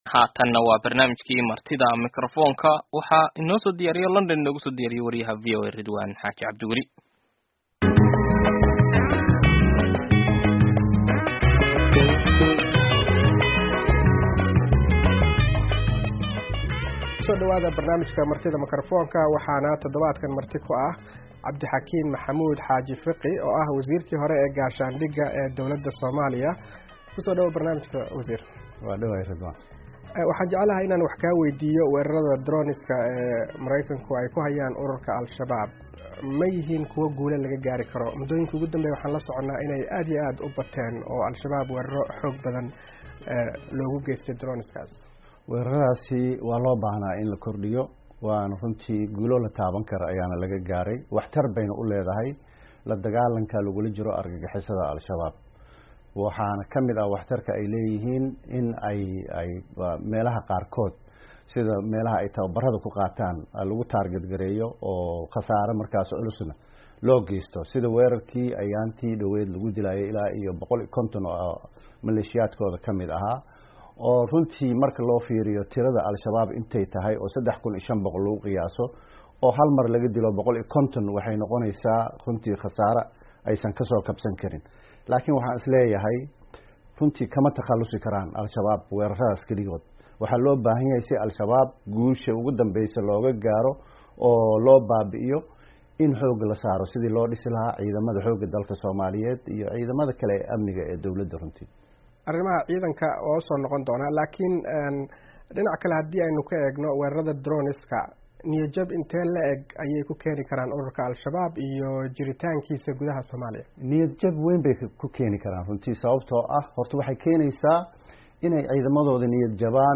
Barnaamijka Martida Mikrofoonka ee toddobaadkan waxa marti ku ah Wasiirkii hore ee gaashaandhigga Somalia Cabdixakim Maxamuud Xaaji Fiqi.